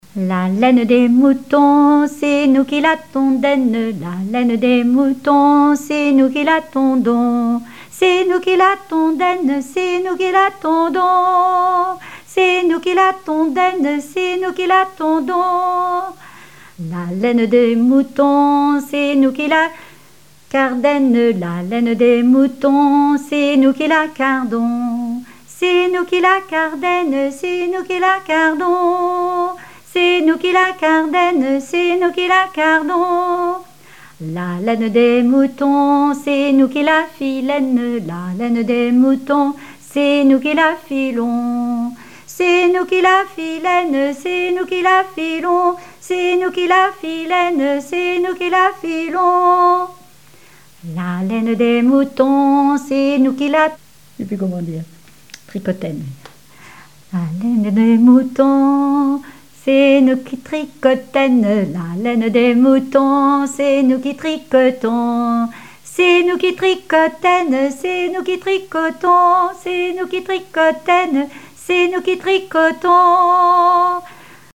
Genre énumérative
chansons populaires et traditionnelles
Pièce musicale inédite